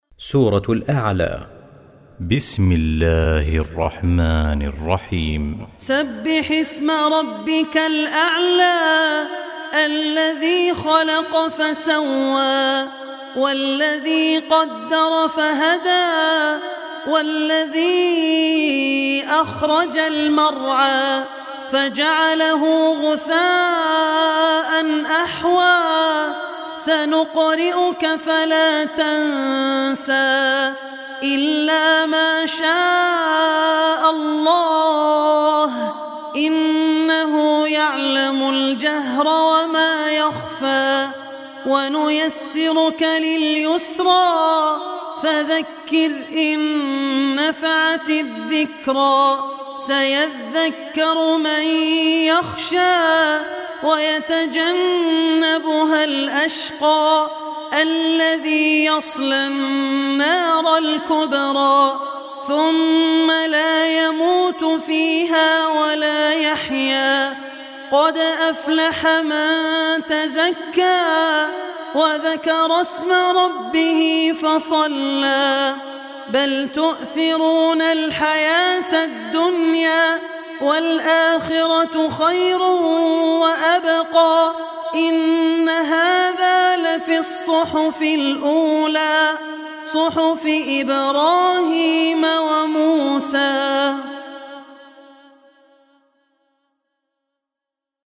Surah Ala Recitation